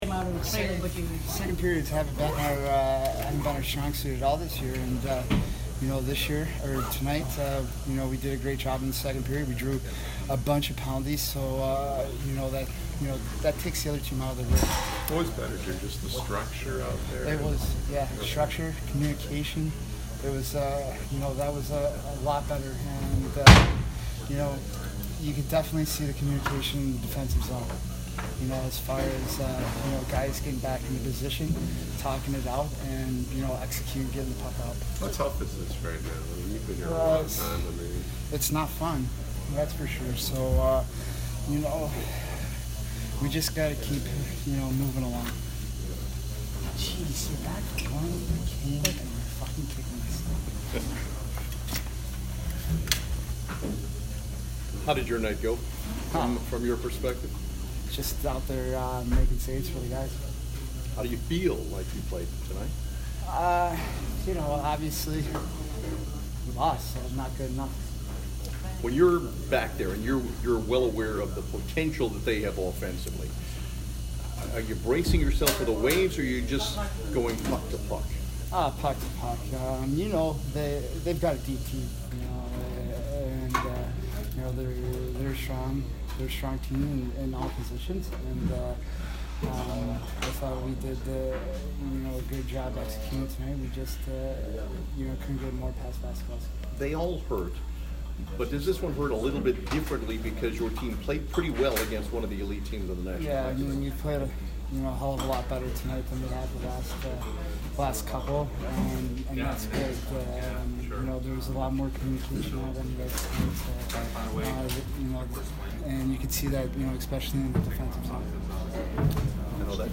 Jimmy Howard post-game 10/18